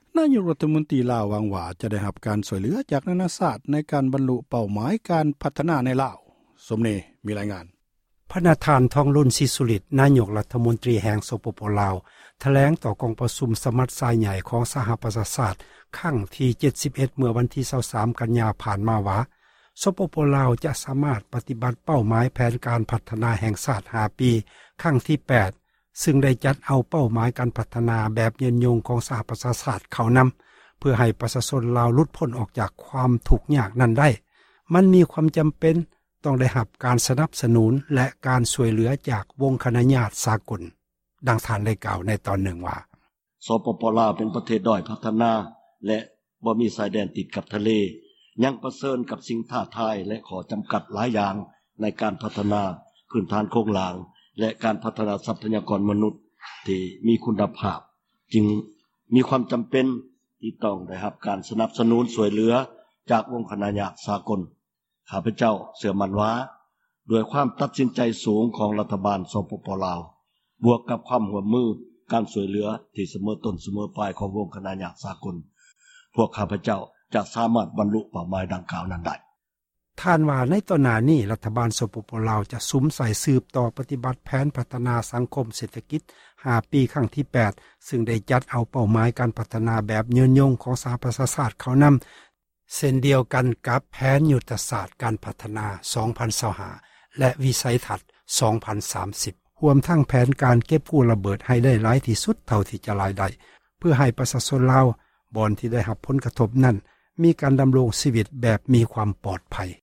ທ່ານ ທອງລຸນ ສີສຸລິດ ນາຍົກ ຣັຖມົນຕຣີ ສປປລາວ ຖແລງຕໍ່ ກອງປະຊຸມ ສະມັດຊາໃຫ່ຽ ສະຫະປະຊາຊາຕ ຄັ້ງທີ່ 71 ເມື່ອວັນທີ 23 ກັນຍາ ຜ່ານມາ ວ່າ ສປປລາວ ຈະສາມາດ ປະຕິບັດ ເປົ້າໝາຍ ແຜນການ ການພັທນາ ແຫ່ງຊາຕ 5 ປີ ຄັ້ງທີ 8 ຊຶ່ງ ໄດ້ຈັດເອົາ ເປົ້າ ໝາຍ ການພັທນາ ແບບຍືນຍົງ ຂອງ ສະຫະປະຊາຊາຕ ເຂົ້ານໍາເພື່ອໃຫ້ ປະຊາຊົນລາວ ຫລຸດພົ້ນຈາກ ຄວາມທຸກຍາກ ໄດ້ນັ້ນ ມັນມີ ຄວາມຈໍາເປັນ ຕ້ອງໄດ້ຮັບ ການ ສນັບສນູນ ແລະ ການຊ່ວຍເຫຼືອ ຈາກ ວົງຄະນາຍາຕ ສາກົນ. ດັ່ງທ່ານ ໄດ້ກ່າວ ໃນຕອນນຶ່ງວ່າ: